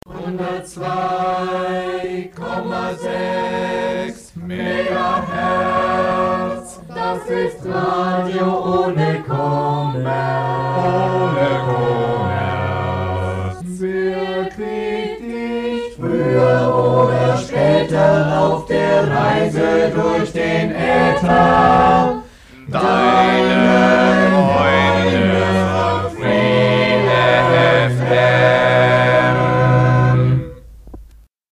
Genre Jingle